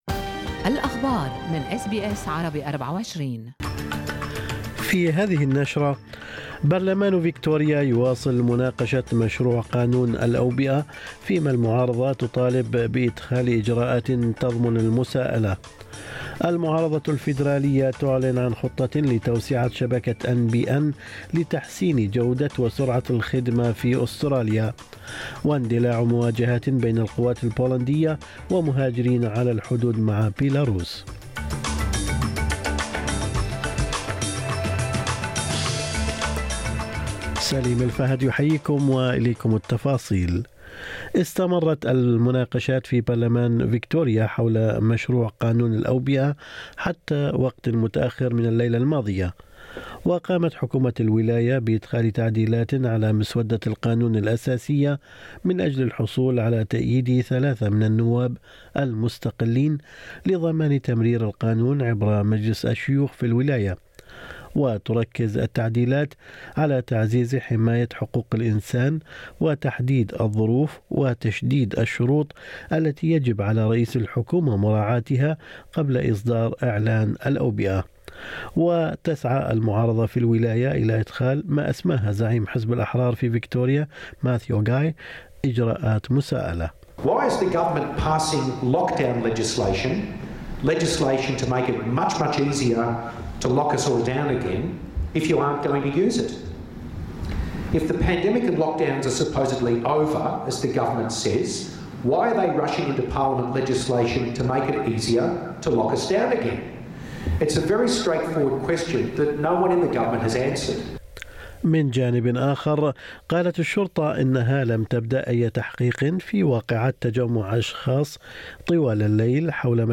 نشرة أخبار الصباح 17/11/2021